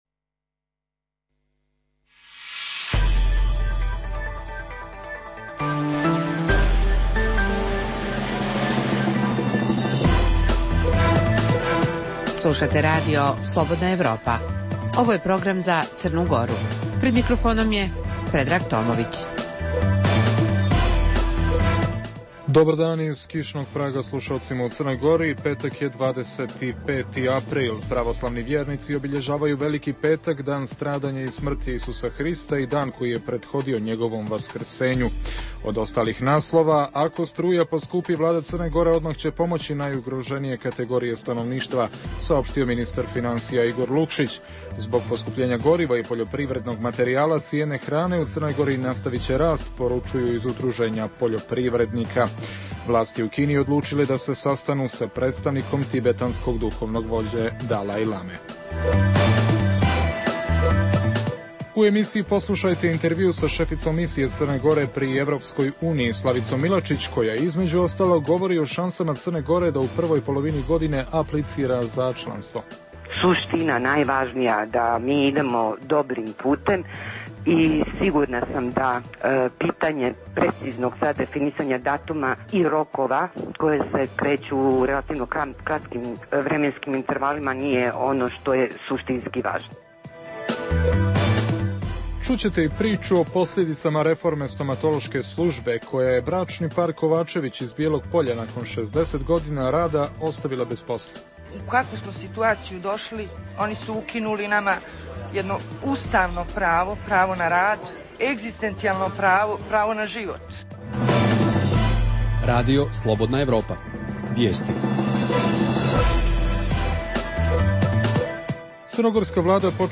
U intervjuu za RSE šefica Misije Crne Gore pri EU Slavica Milačić govori o šansama CG da u prvoj polovini godine aplicira za članstvo.